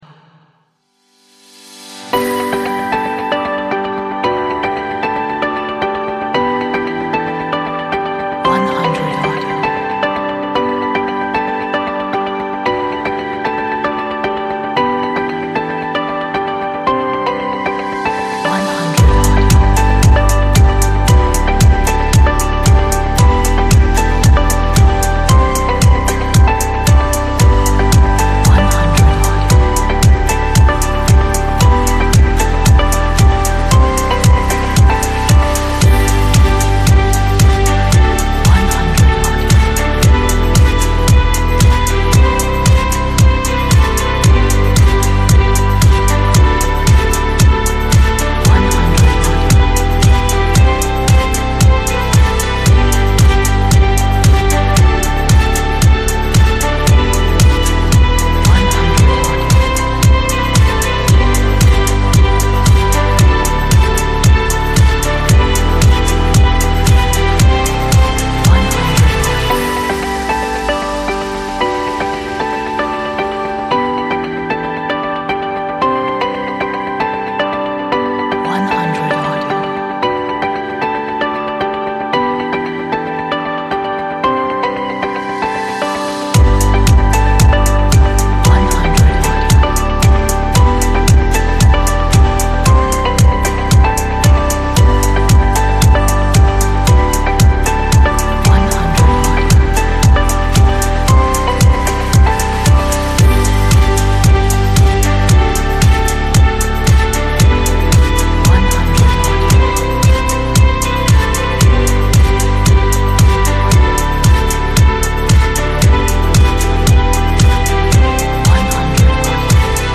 a nice pop upbeat inspirational track